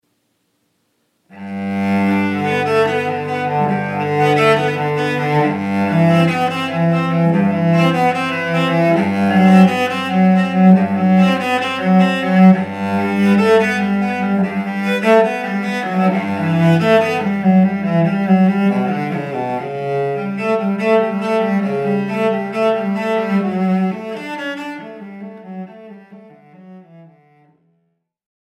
Classical Ensembles
Let the elegant sounds of the Violin, Cello, Flute, Viola, Harp, Piano and Classical Guitar add the perfect touch to your event!
Classical